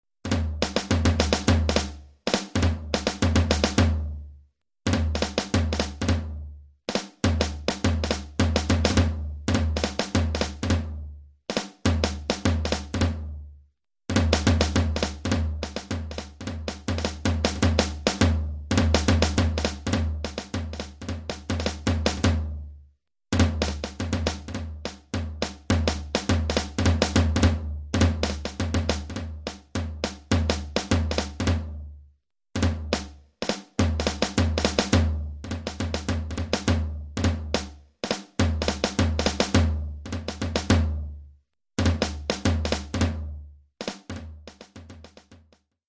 Oeuvre pour tambour seul.